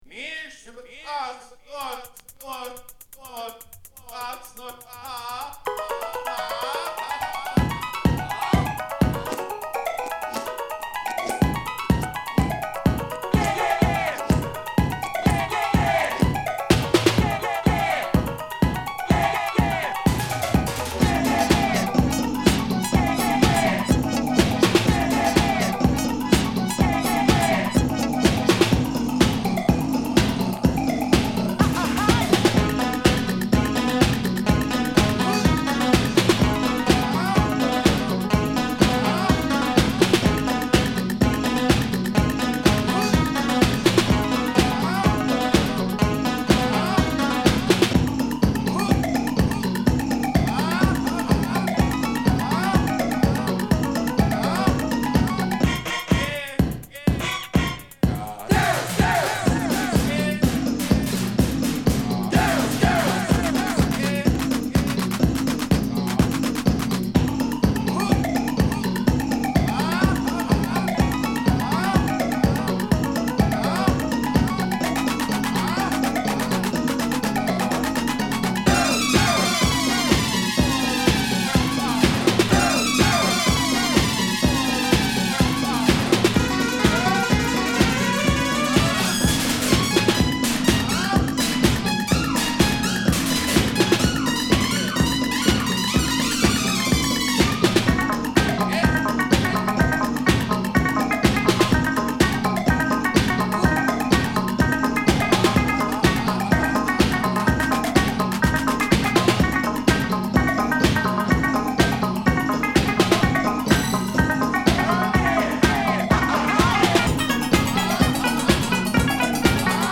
ボーナスビーツにダブヴァージョン、スロウテンポのエレクトロB2